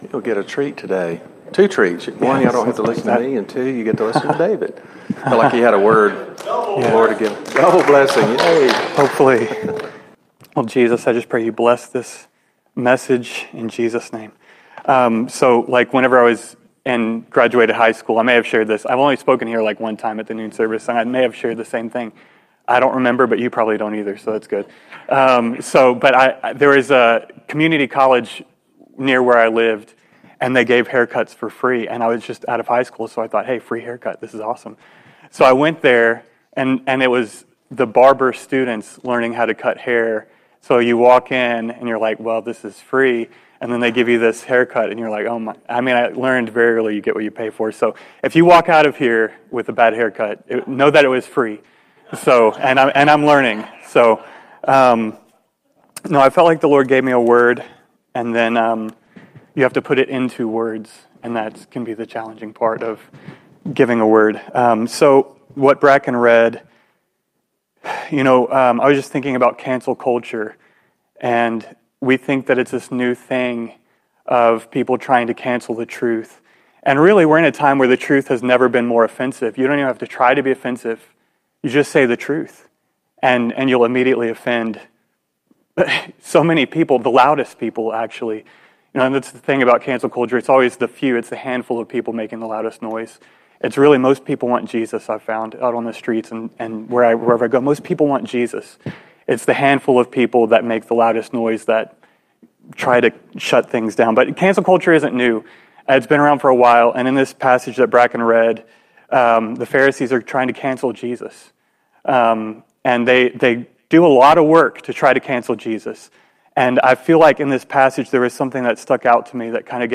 Service Type: Conference